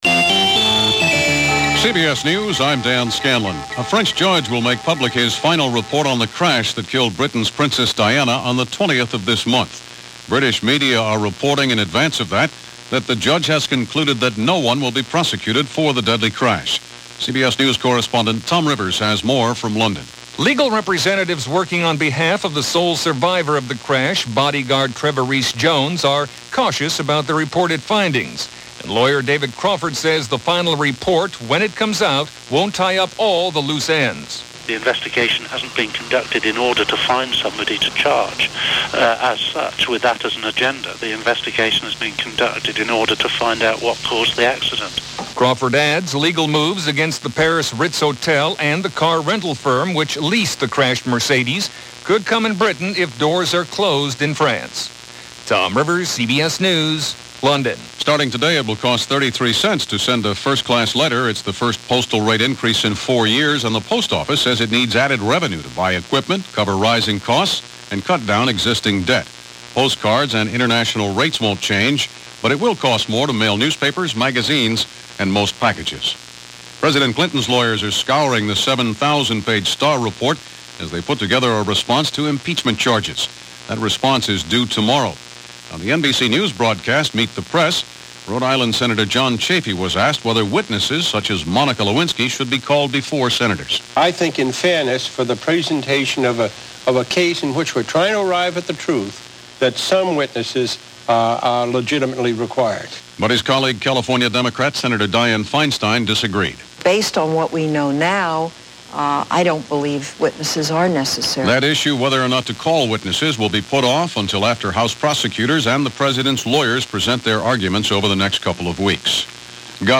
And that’s a small slice of what happened on January 10, 1999 as presented by CBS Radio News On The Hour.